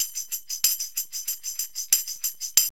TAMB LP 94.wav